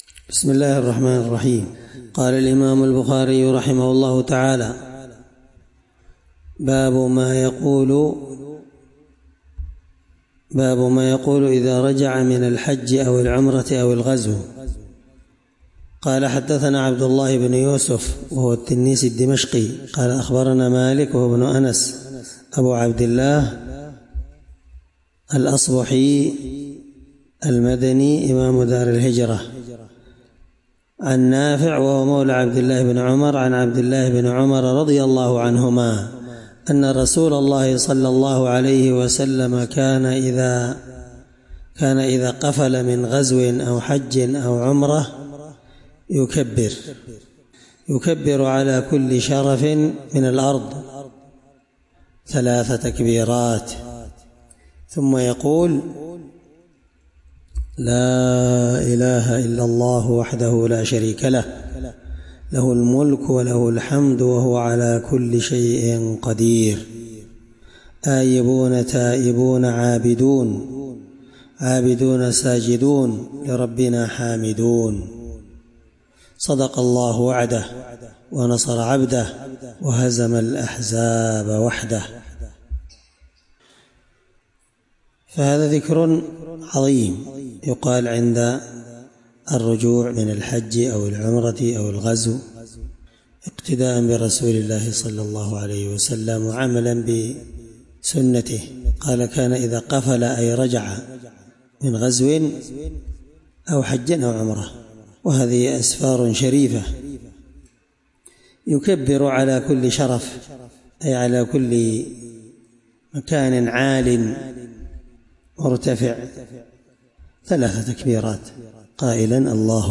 الدرس 10من شرح كتاب العمرة حديث رقم(1797)من صحيح البخاري